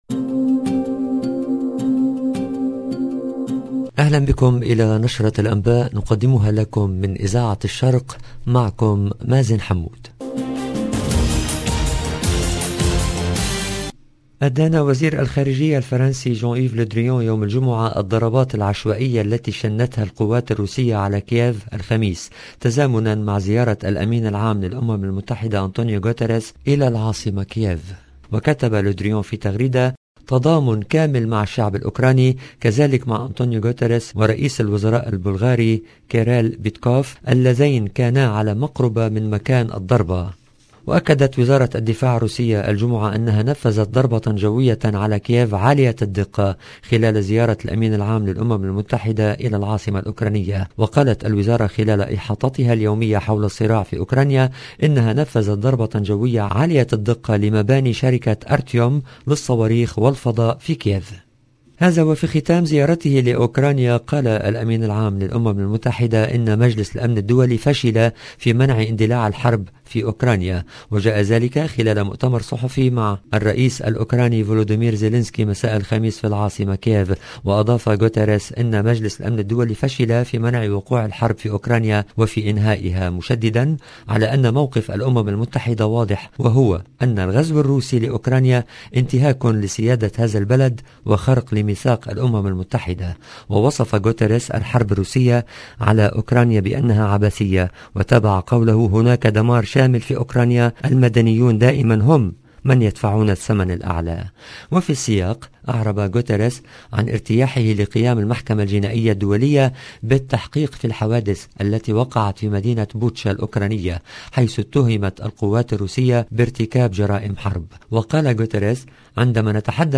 LE JOURNAL DU SOIR EN LANGUE ARABE DU 29/04/22